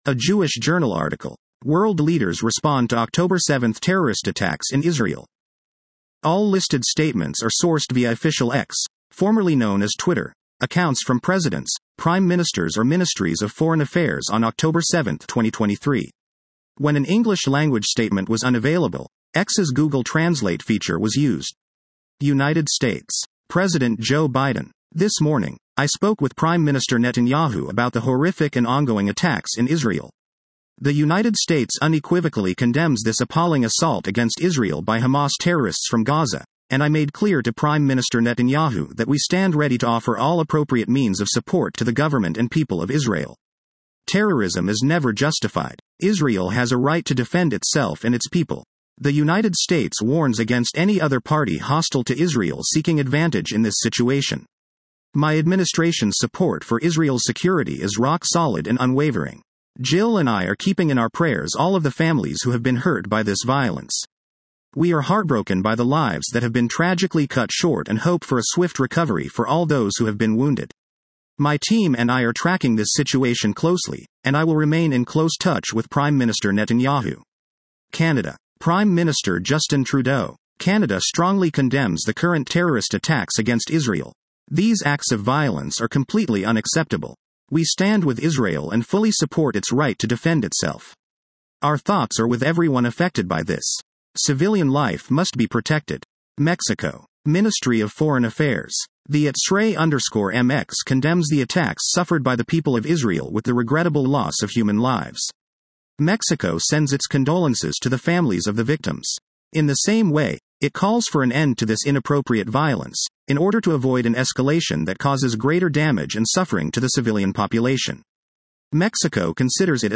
Statements from Presidents, Prime Ministers and Ministries of Foreign Affairs.